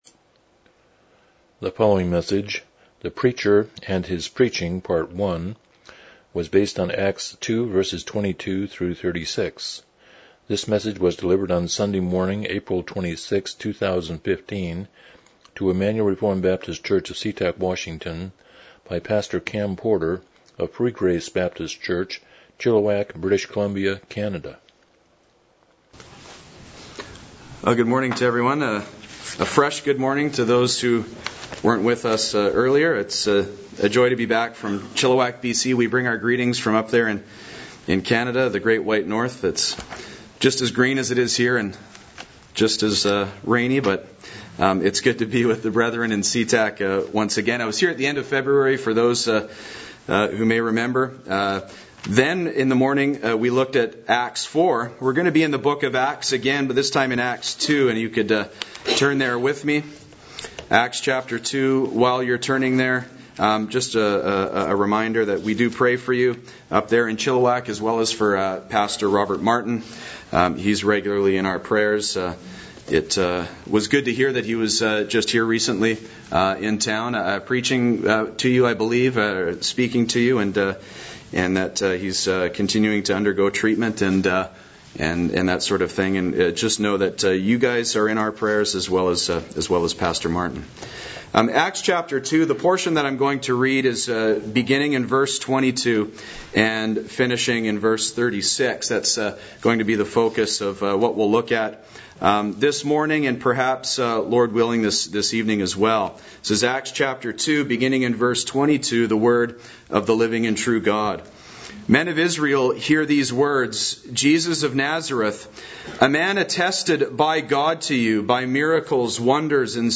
Acts 2:22-36 Service Type: Morning Worship « A New Heaven and a New Earth The Preacher and His Preaching